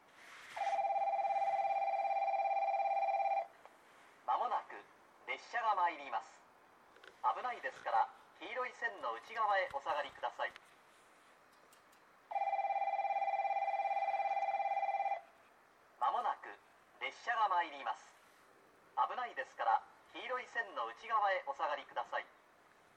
この駅では接近放送が設置されています。
接近放送普通　国分行き接近放送です。